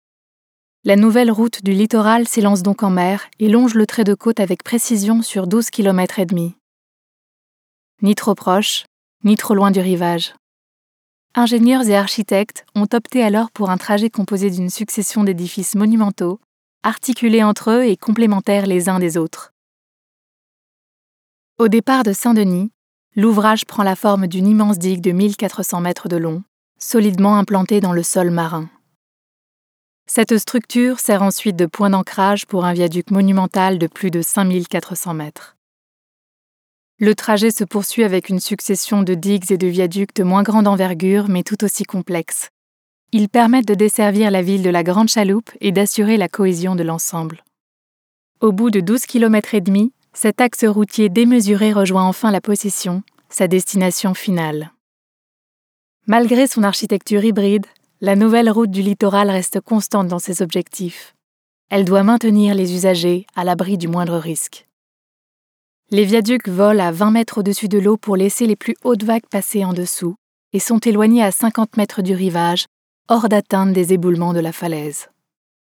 Voix documentaire
Voix off
- Mezzo-soprano